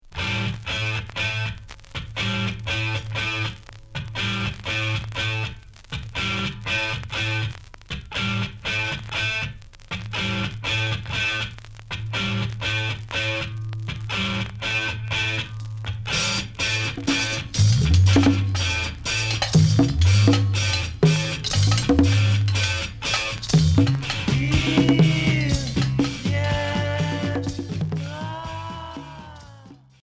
Progressif